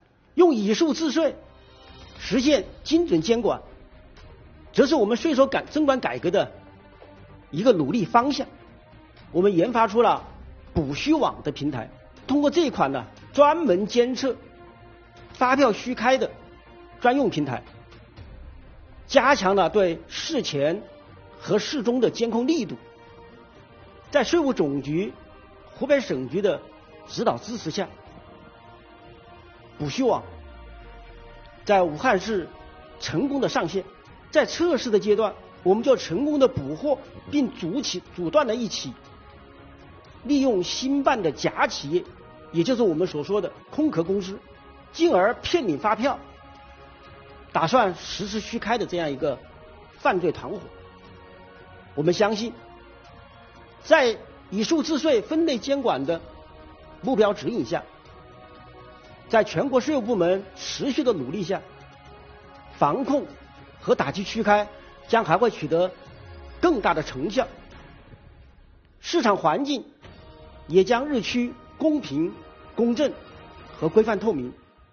7月15日，中共中央宣传部举行中外记者见面会，邀请5名税务系统党员代表围绕“坚守初心 税收为民”主题与中外记者见面交流。